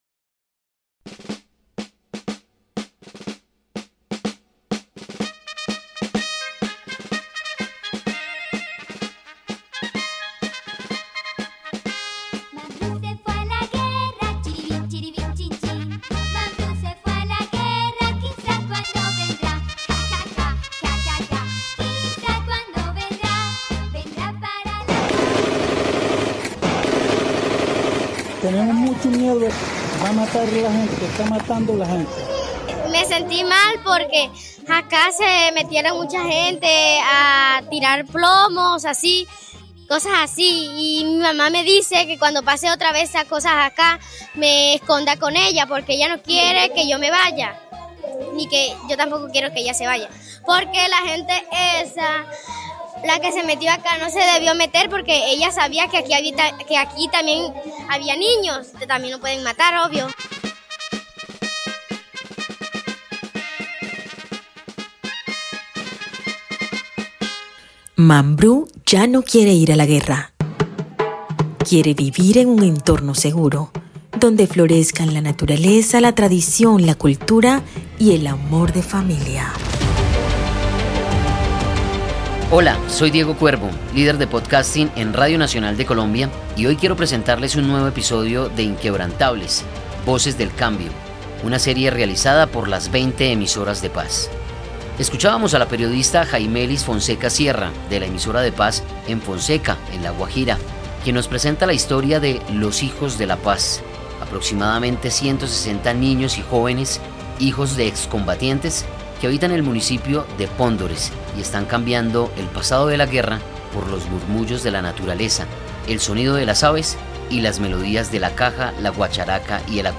Con tambores, vallenato y risas, 160 adolescentes de Pondores, La Guajira, escriben una nueva historia lejos del sonido de las balas. Una generación que nació en medio de la incertidumbre, pero eligió el arte, la música y la esperanza como camino.